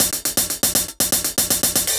Index of /musicradar/ultimate-hihat-samples/120bpm
UHH_AcoustiHatA_120-05.wav